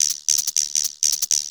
Perc Pattern.wav